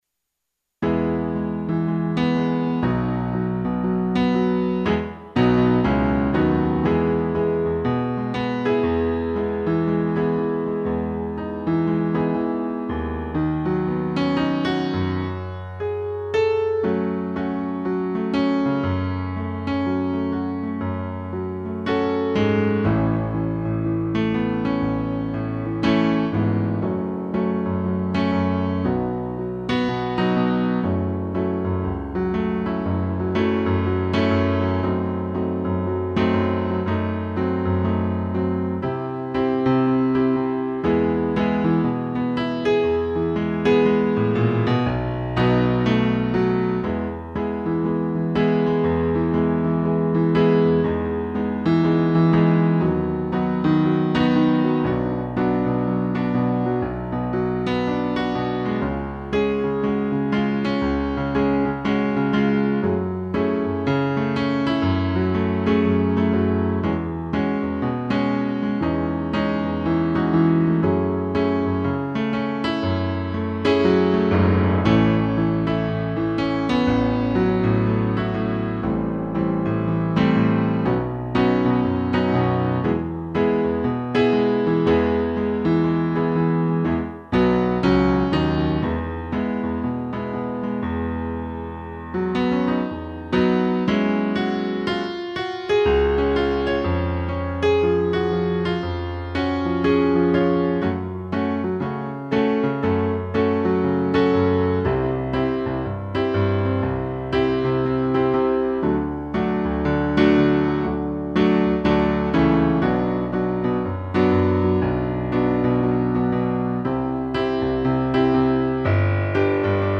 2 pianos
instrumental